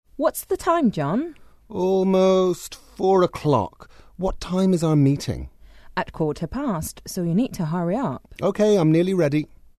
英语初学者口语对话第76集：我们的会议是几点？
english_12_dialogue_1.mp3